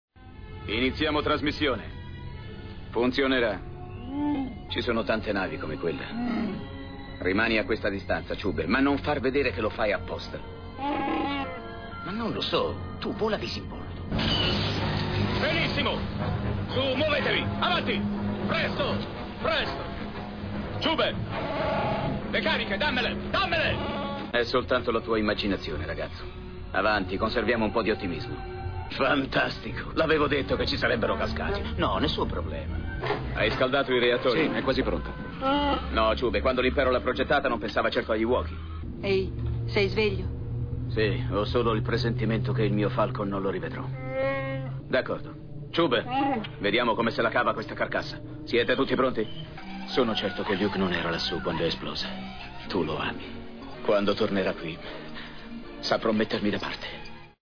voce di Stefano Satta Flores dal film "Il ritorno dello Jedi", in cui doppia Harrison Ford.